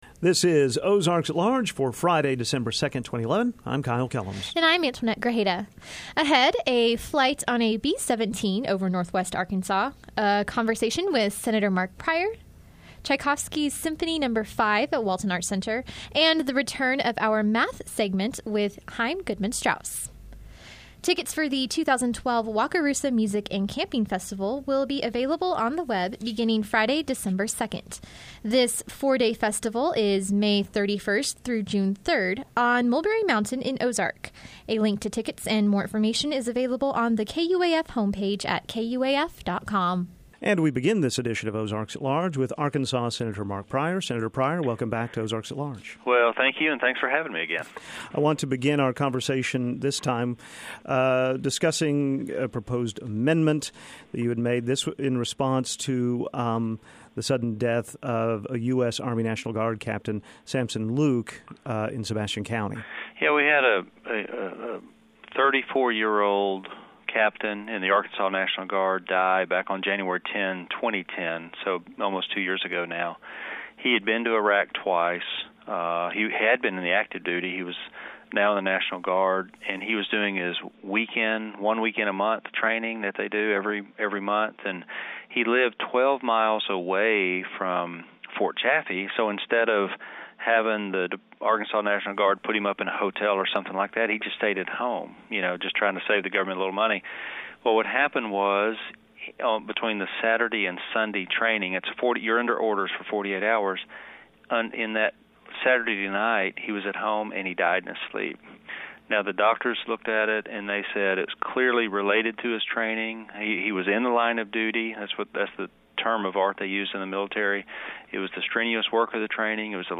and a conversation with Senator Mark Pryor.